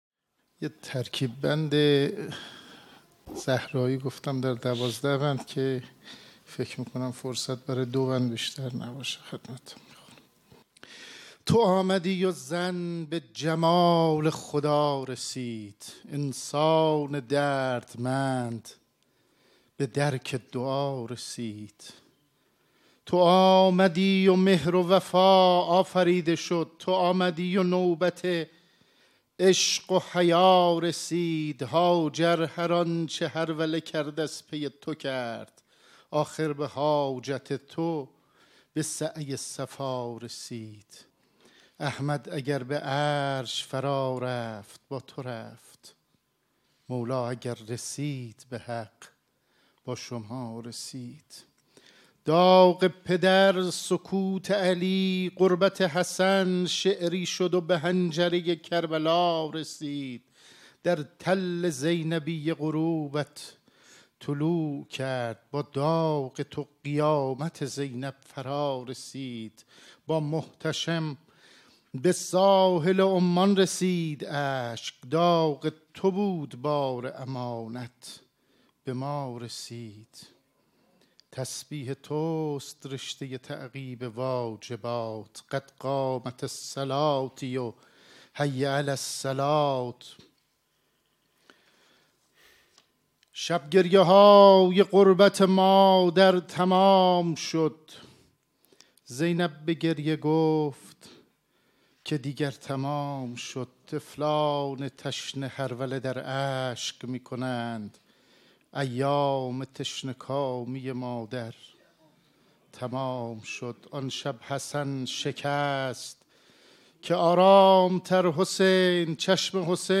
شعرخوانی | تو آمدی و زن به جمال خدا رسید
شعر خوانی علیرضا قزوه در محضر رهبر انقلاب | محفل شاعران آئینی | حسینیه امام خمینی(ره)